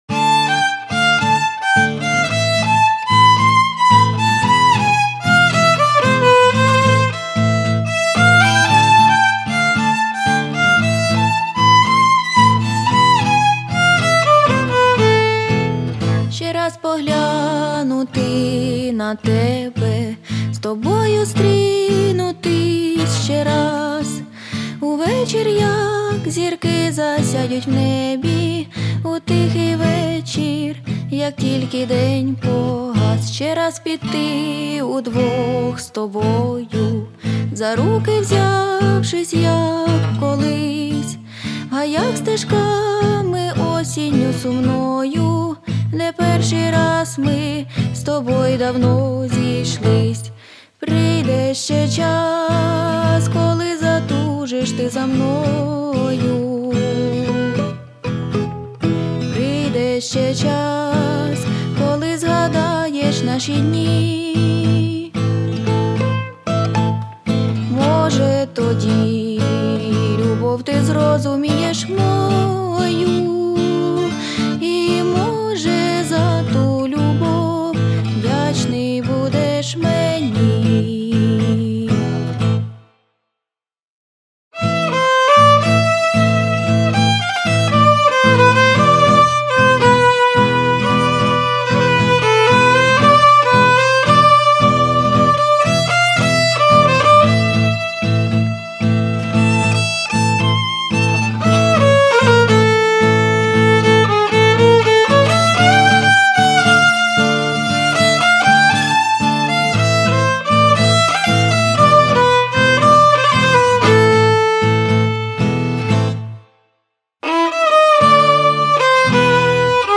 Priqde_sche_chas__tango.mp3